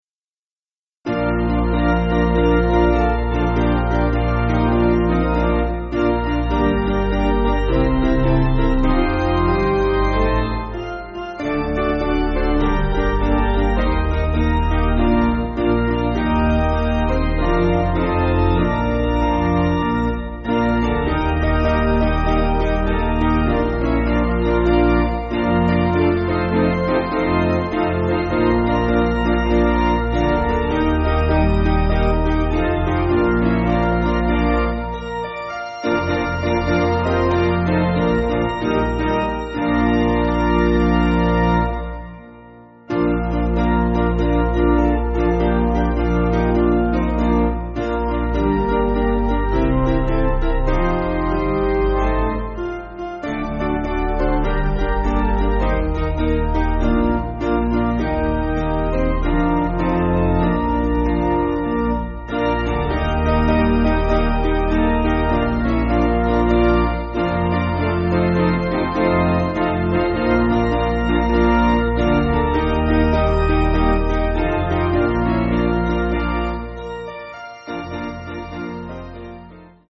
Basic Piano & Organ
(CM)   5/Bb